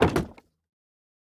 Minecraft Version Minecraft Version 1.21.5 Latest Release | Latest Snapshot 1.21.5 / assets / minecraft / sounds / block / bamboo_wood_door / toggle4.ogg Compare With Compare With Latest Release | Latest Snapshot